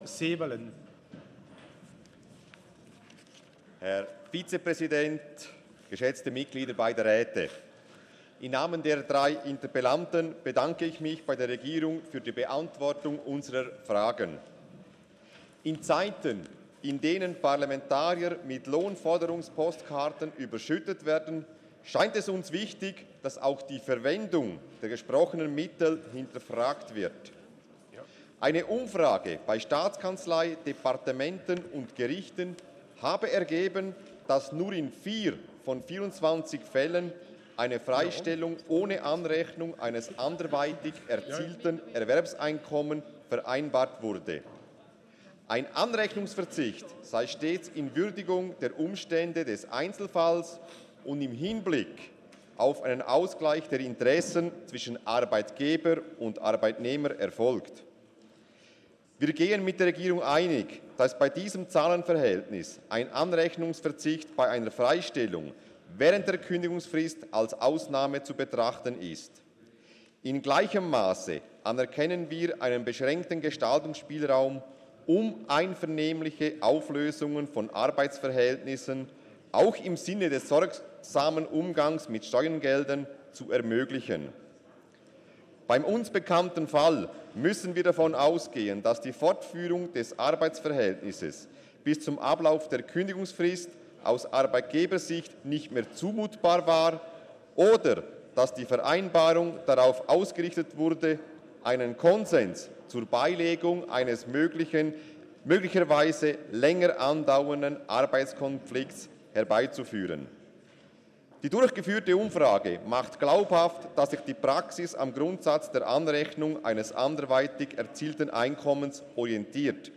19.2.2019Wortmeldung
Session des Kantonsrates vom 18. und 19. Februar 2019